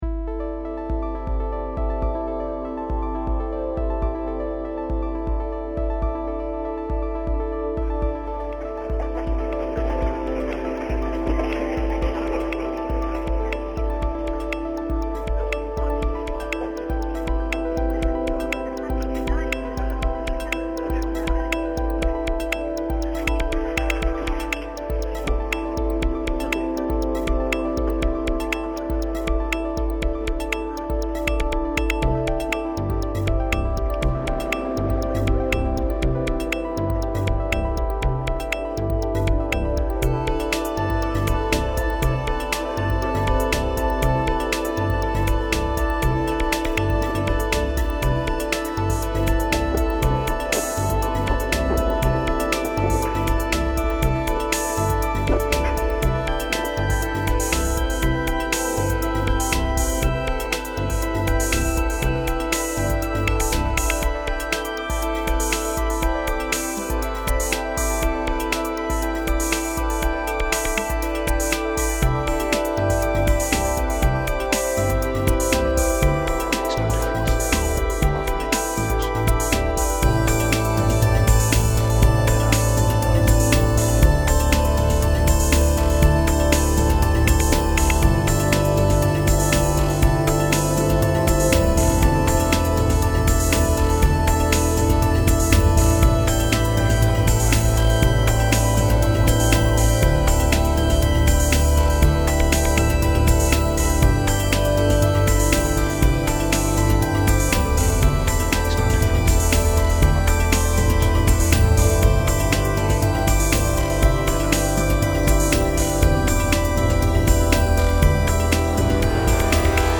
I finished an EP a while back and just accidently mixed them together - thought it sounded quite good, better than the original tracks in fact: